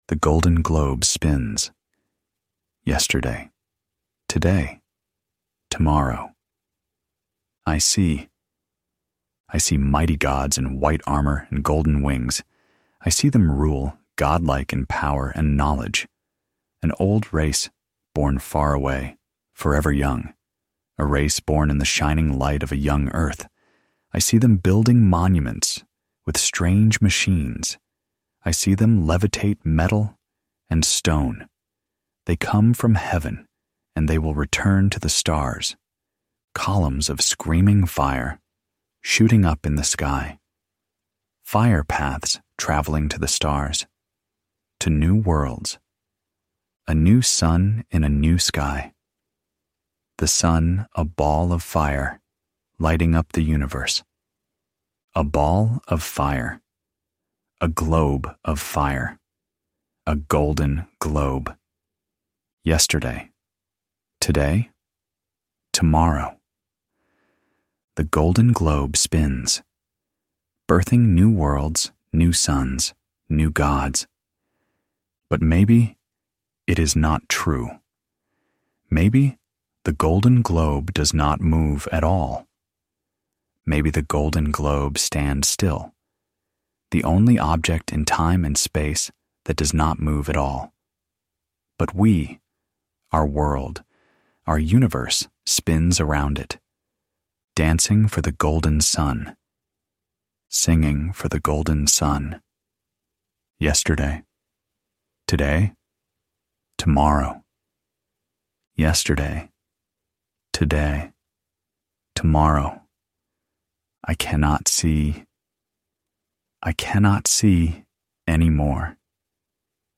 Here is a small excerpt, as an audio file, the end of a story, from the writings of the monk Damian of Seda.